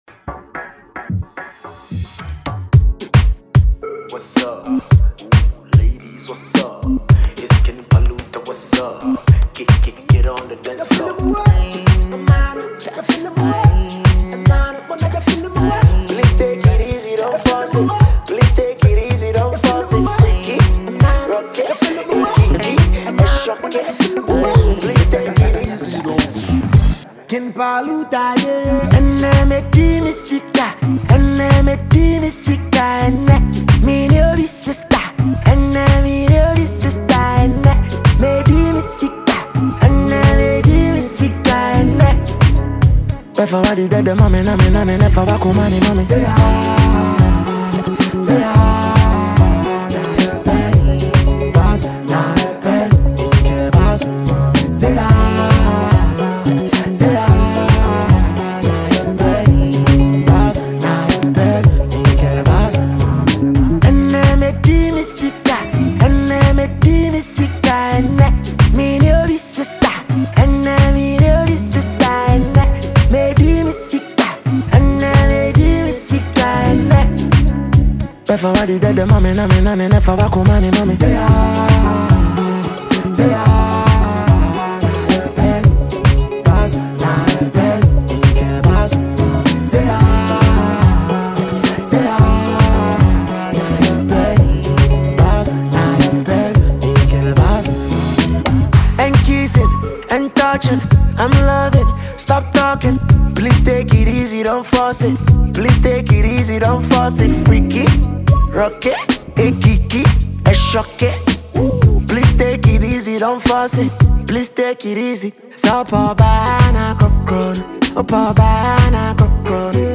a versatile singer and rapper
a solo track